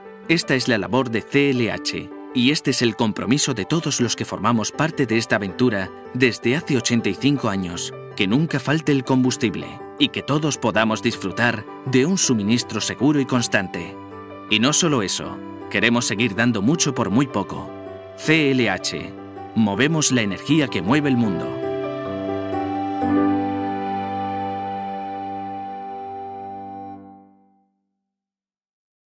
Locutor profesional, actor de doblaje y técnico de sonido con más de 10 años de experiencia en el mundo del doblaje y la locución.
kastilisch
Sprechprobe: Industrie (Muttersprache):